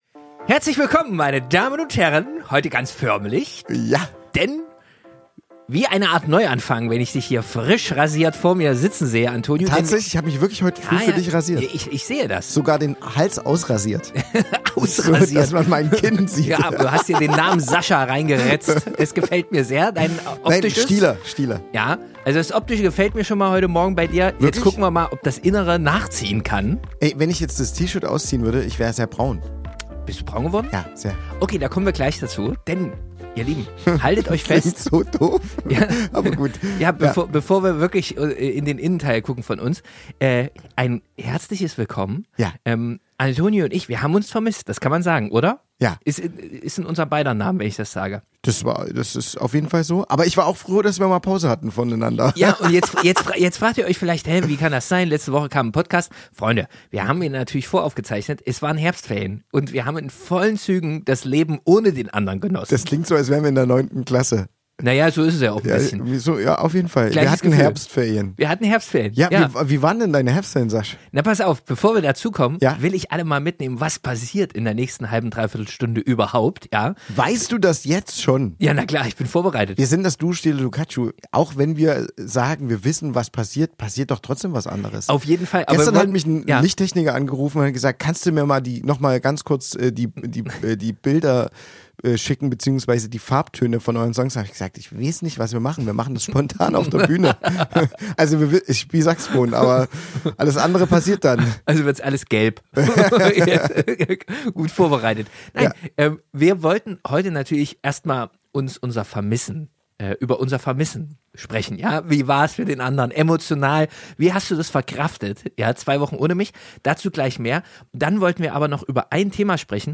Zurück im Podcast-Studio sprechen wir über das Im-Moment-Sein, das Wiedersehen – und die Frage, wie wichtig Pausen in einer Freundschaft eige...